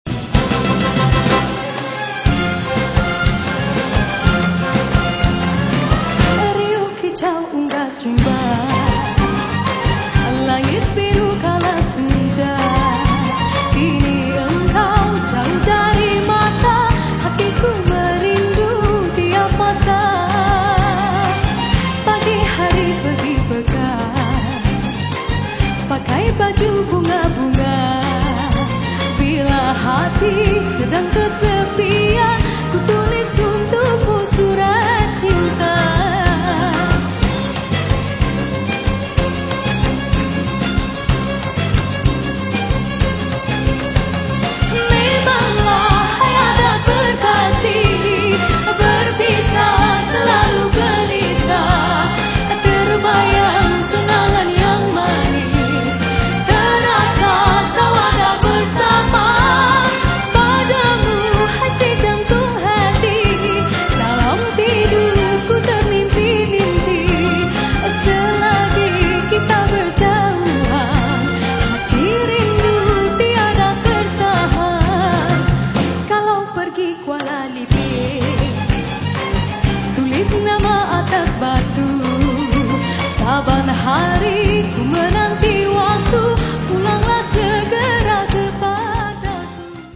Was £14.99. Complete with a symphony orchestra